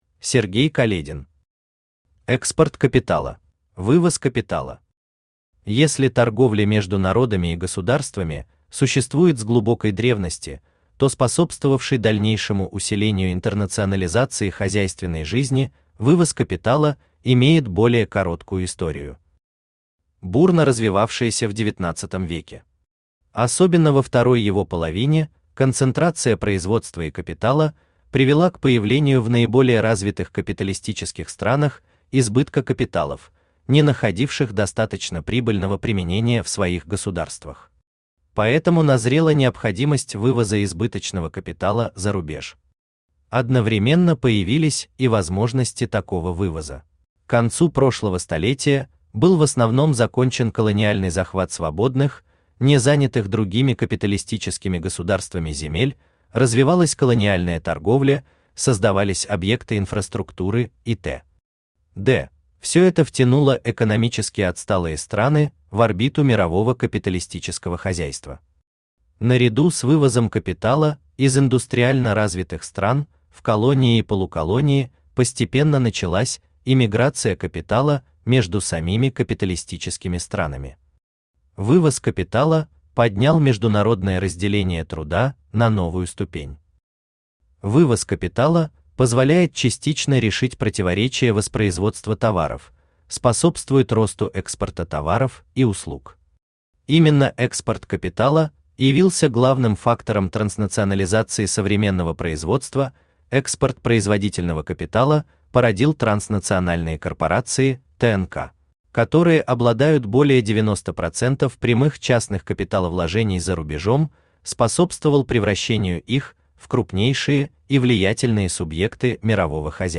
Аудиокнига Экспорт капитала | Библиотека аудиокниг
Aудиокнига Экспорт капитала Автор Сергей Каледин Читает аудиокнигу Авточтец ЛитРес.